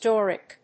音節Dor・ic 発音記号・読み方
/dˈɔːrɪk(米国英語), ˈdɔ:rɪk(英国英語)/